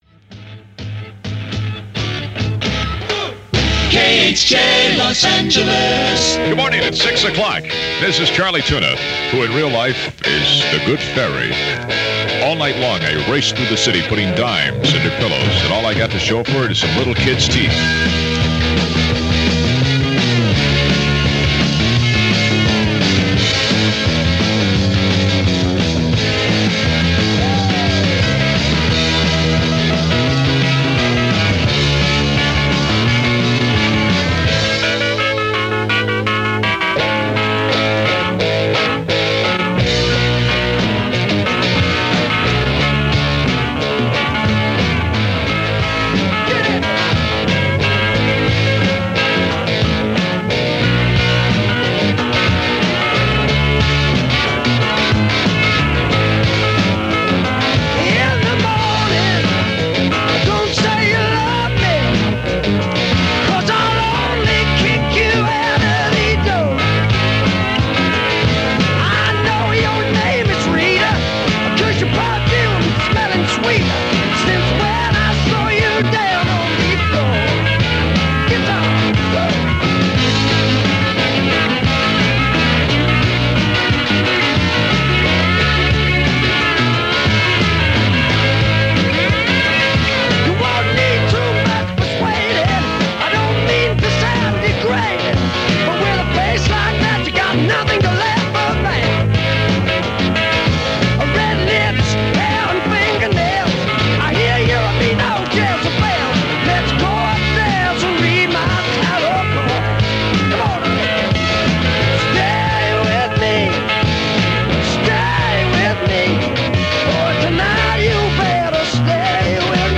And beyond all that, there’s still your radio – and there’s still top-40 and there’s still KHJ. And every morning your alarm blasts Charlie Tuna – he’s the guy who gets you to the bus on time, just like clockwork and the one thing you can count on.